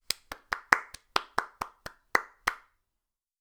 solo-clap.wav